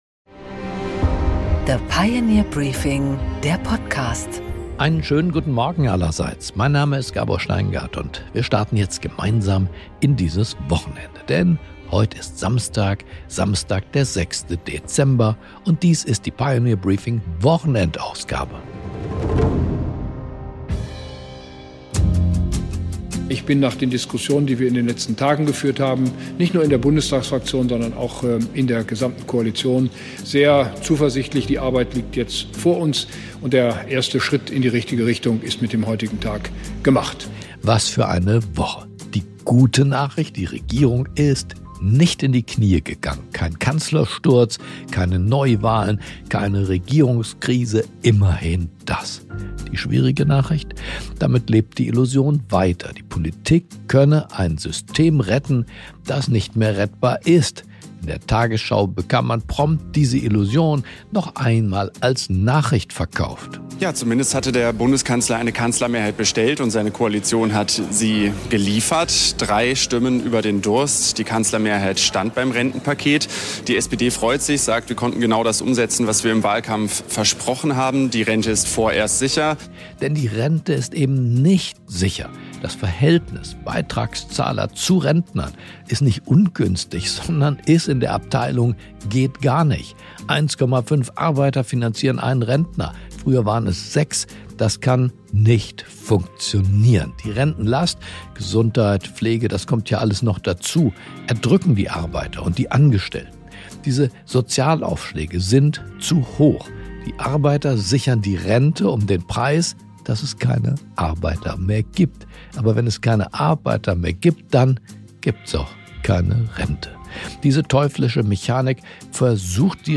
Gabor Steingart präsentiert die Pioneer Briefing Weekend Edition.